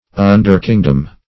Search Result for " underkingdom" : The Collaborative International Dictionary of English v.0.48: Underkingdom \Un"der*king`dom\, n. A subordinate or dependent kingdom.